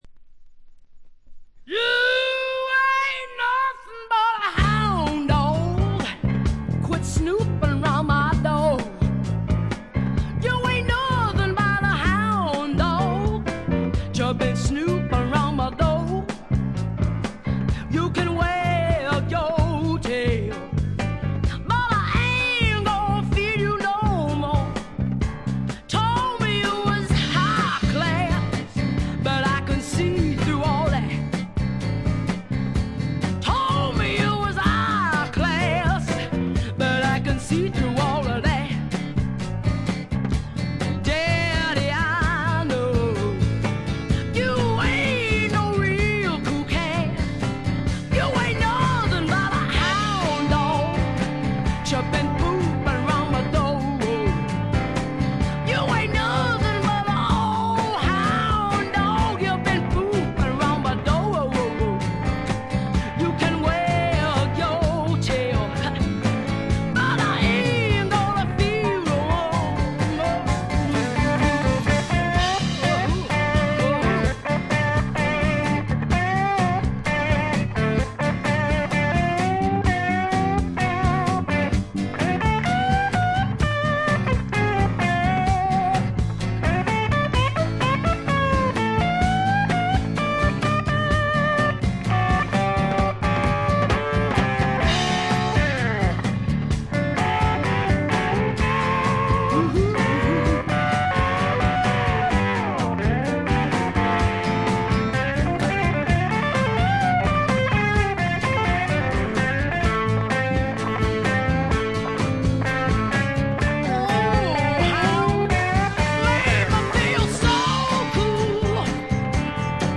ほとんどノイズ感無し。
ニューヨーク録音、東海岸スワンプの代表作です。
いかにもイーストコーストらしい機知に富んだスワンプアルバムです。
試聴曲は現品からの取り込み音源です。
Guitar, Lead Vocals
Keyboards, Vocals
Percussion
Violin, Vocals
Bass
Banjo, Guitar (Steel)